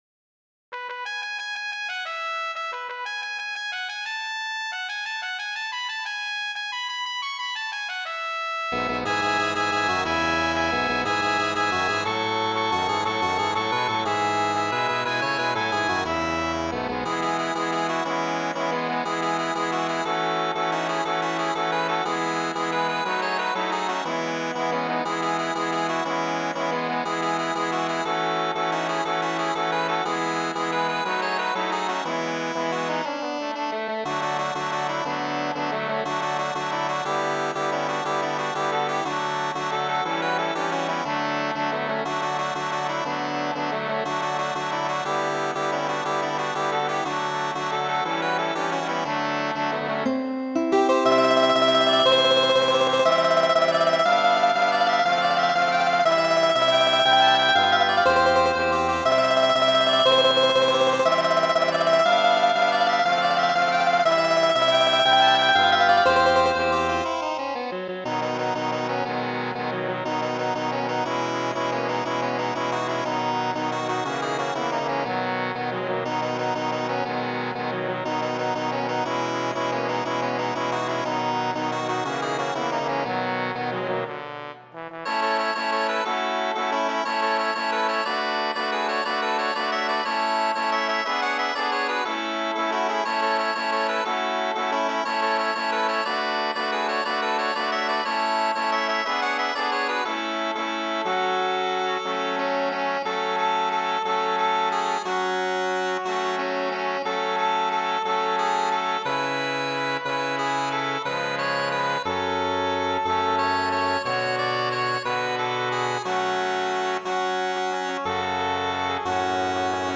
Muziko:
Himno de Riego, variita de mi mem.